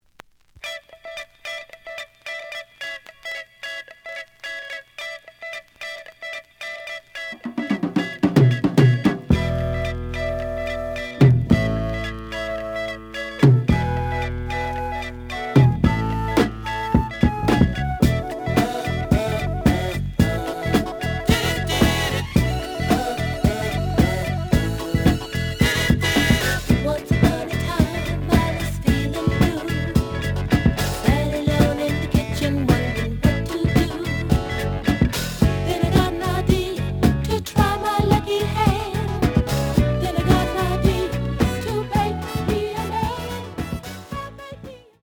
The audio sample is recorded from the actual item.
●Genre: Soul, 70's Soul
Slight noise on beginning of both sides, but almost good.